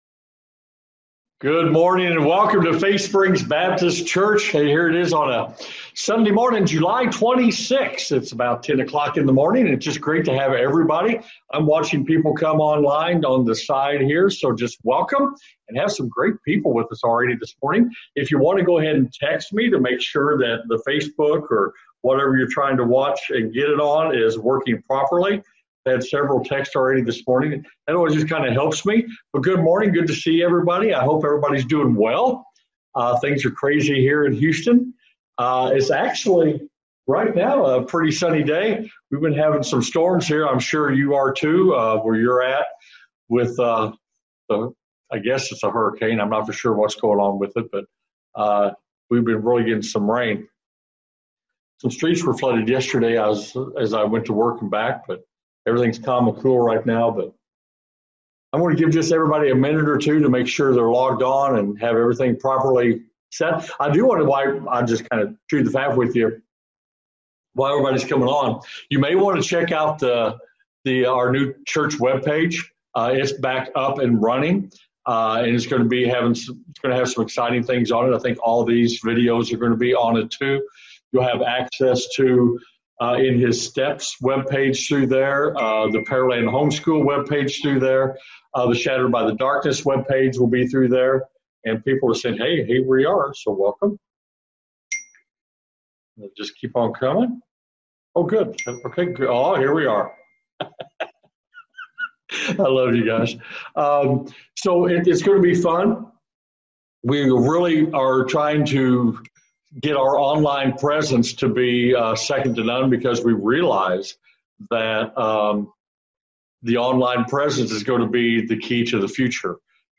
FaithSprings Baptist LIVE, July 26, 2020